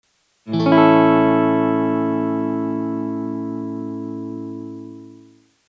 پکیج ریتم و استرام گیتار الکتریک ایرانی
دموی صوتی استرام آهسته :
strum-2.mp3